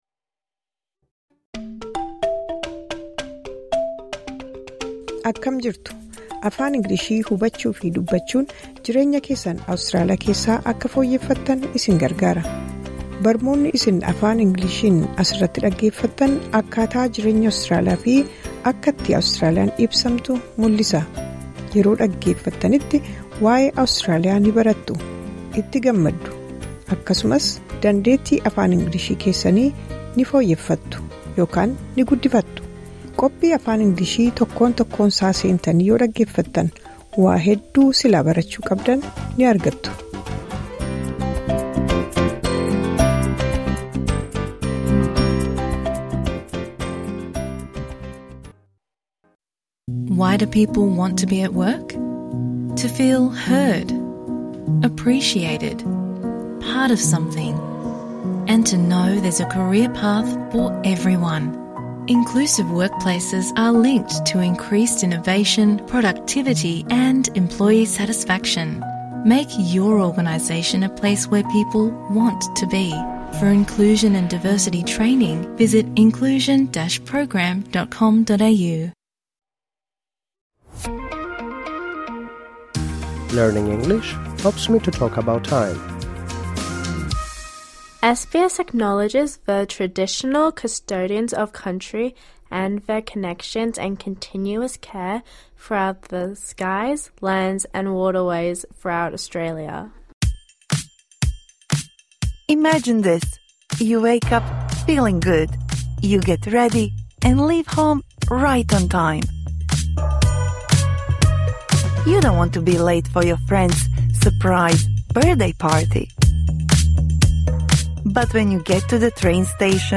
This bonus episode provides interactive speaking practice for the words and phrases you learnt in Episode #86 Talking about time (Med).